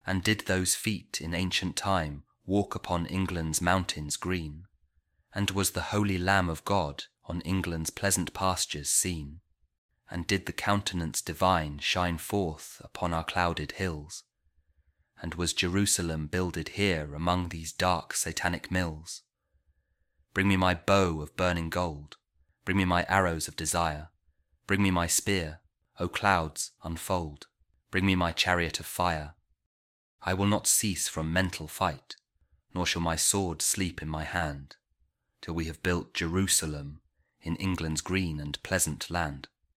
William Blake | Jerusalem | Audio | Christian Poem
blake-jerusalem-audio-poem.mp3